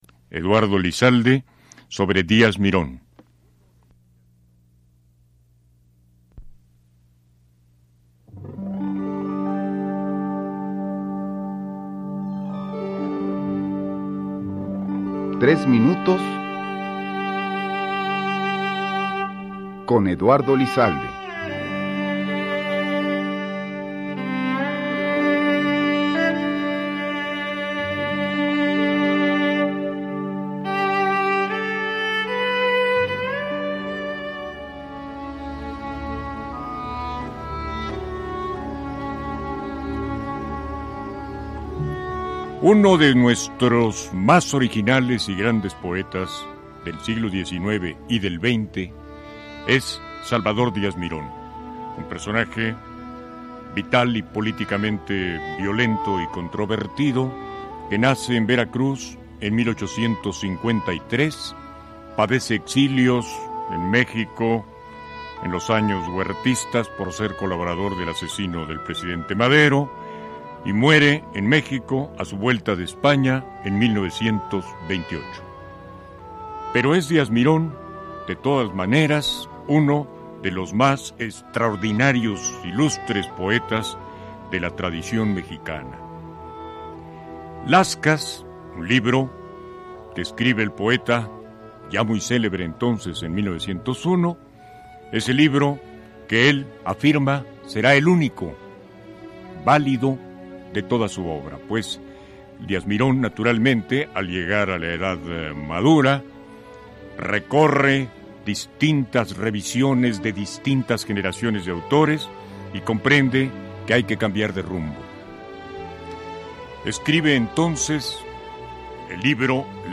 Escucha a Eduardo Lizalde, en su programa “Tres minutos con”, quien presenta tres cápsulas sobre Díaz Mirón, las cuales se transmitieron en 1992.